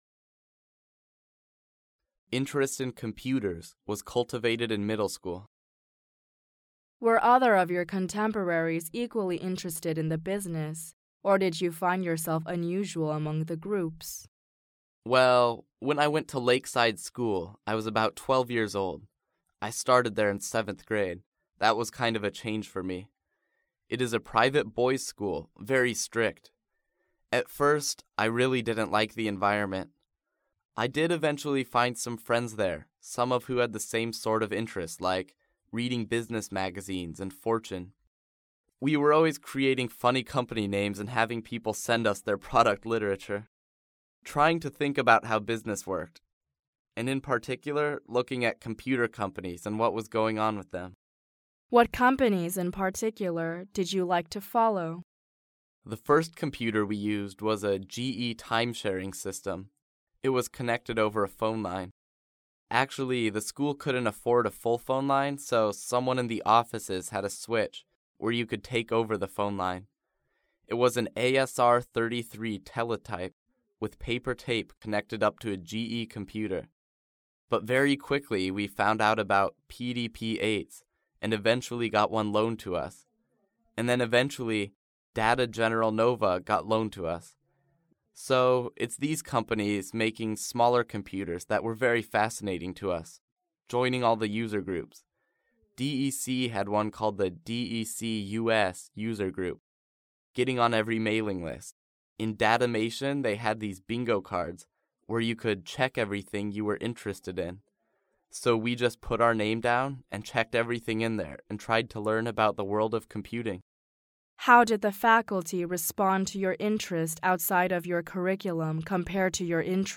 创业成功人士访谈录 第7期:比尔盖茨(1) 听力文件下载—在线英语听力室